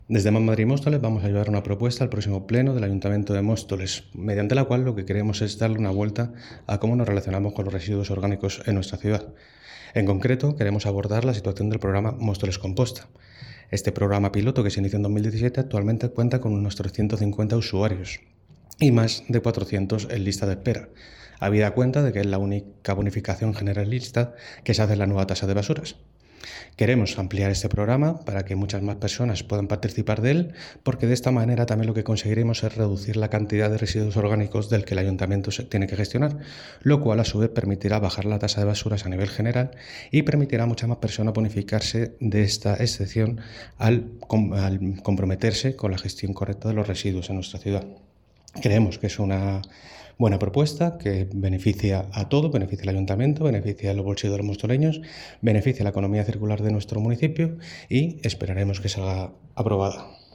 declaraciones-jesus-arrabe-mostoles-composta.mp3